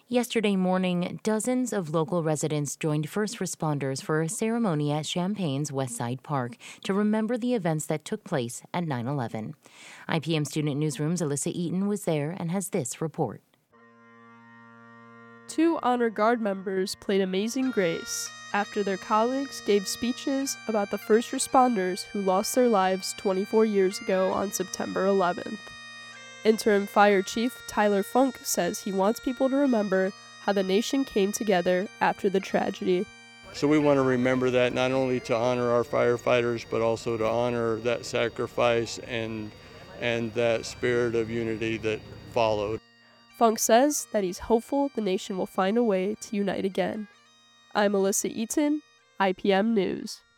At West Side Park, an American flag was raised to half-staff and “Amazing Grace” was played by two Champaign Fire Honor Guard members at a ceremony to commemorate the lives of first responders who were lost during the 9/11 attacks 24 years ago today.